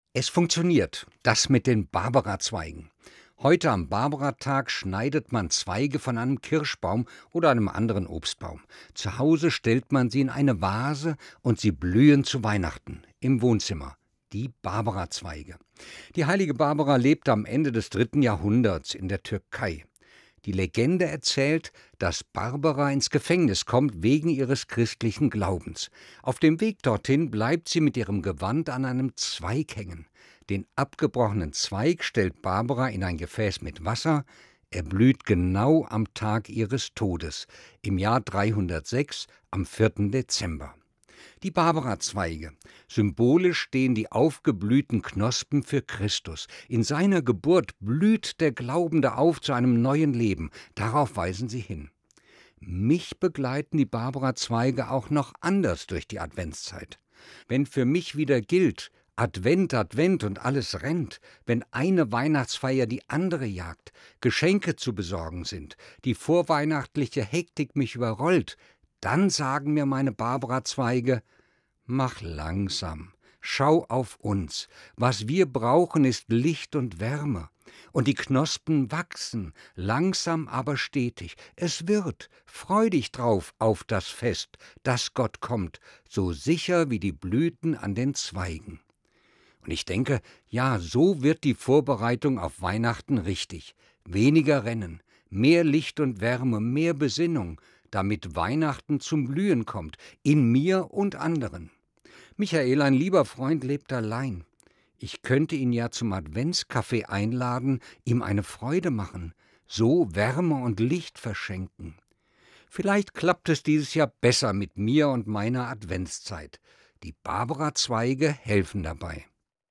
Evangelischer Pfarrer, Fulda